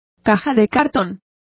Complete with pronunciation of the translation of cartons.